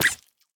assets / minecraft / sounds / mob / axolotl / hurt2.ogg
hurt2.ogg